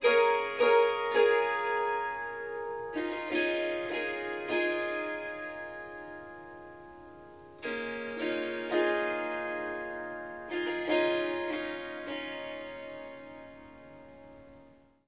Swarmandal Indian Harp Riffs " Harp Firm Lovely Strumming Riff
这个奇妙的乐器是Swarmandal和Tampura的结合。
它被调到C sharp，但我已经将第四个音符（F sharp）从音阶中删除了。
一些录音有一些环境噪音（鸟鸣，风铃）。